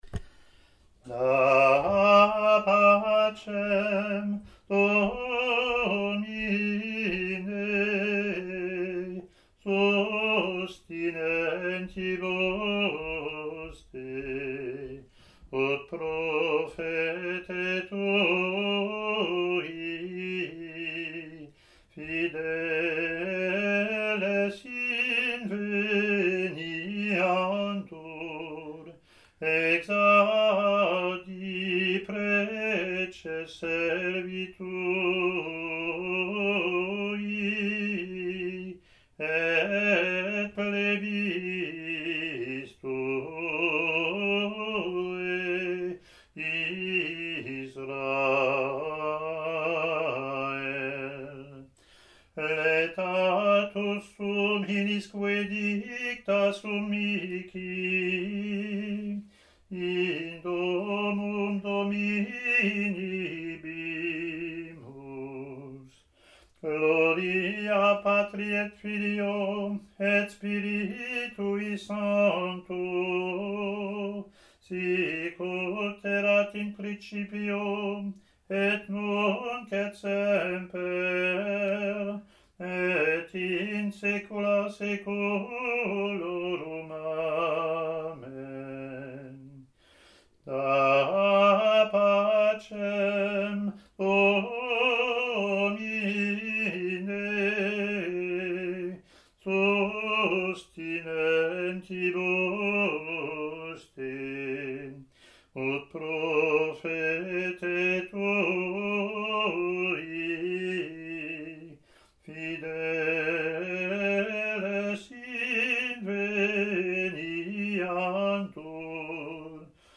Latin antiphon and verse)
ot24-introit-gm.mp3